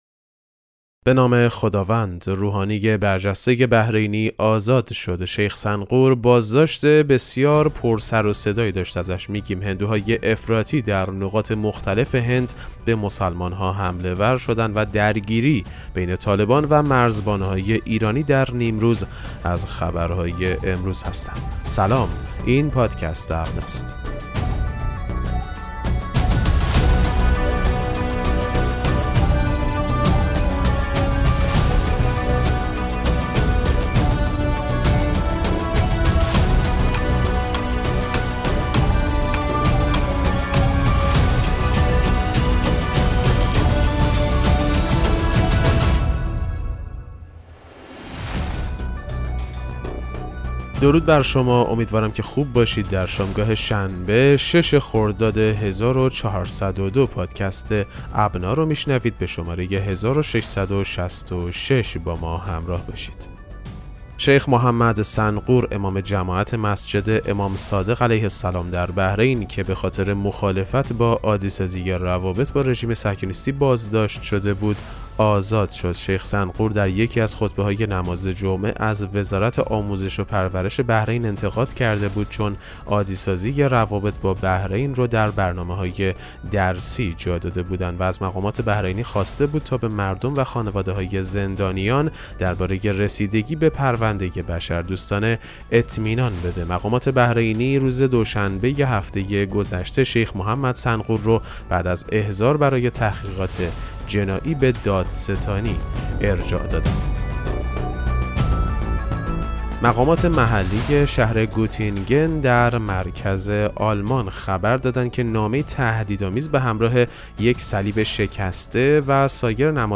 پادکست مهم‌ترین اخبار ابنا فارسی ــ 6 خرداد 1402